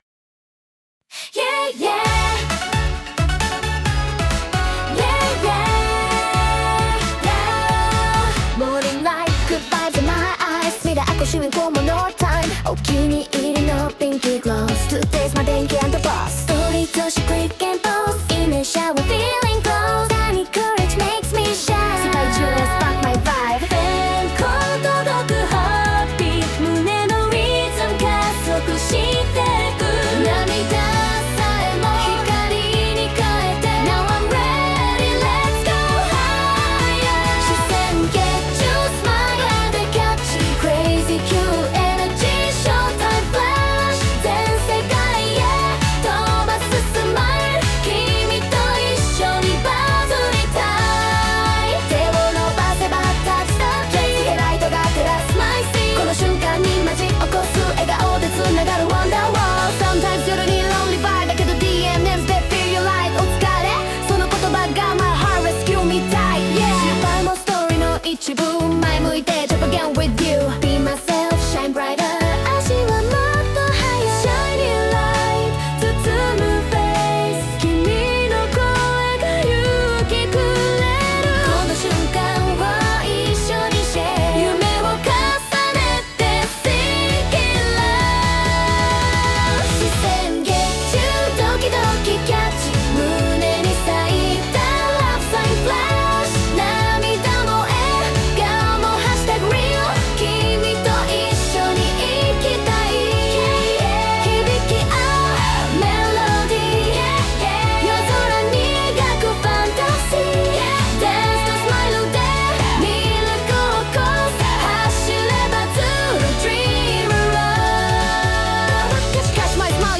ステージのきらめきを感じるアイドルチューン